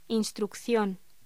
Locución: Instrucción
voz